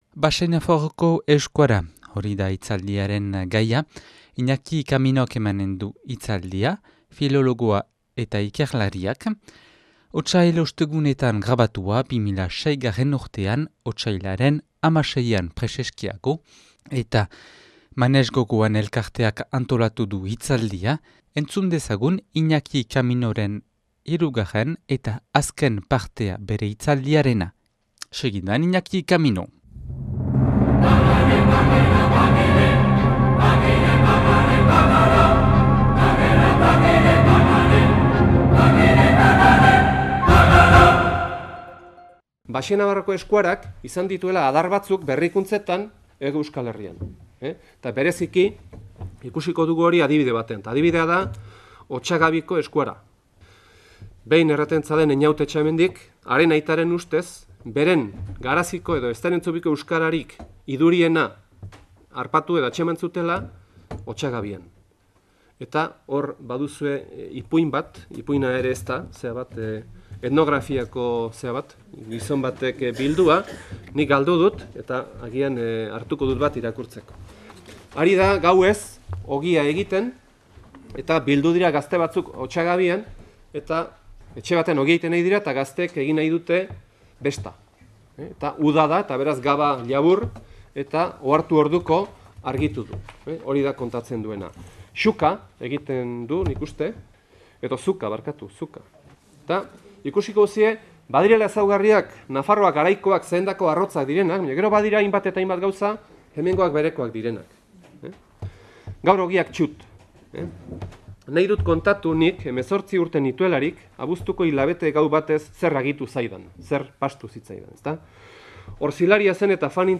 (2006. Otsailaren 16an grabatua Otsail Ostegunetan Donapaleun)